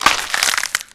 break4.ogg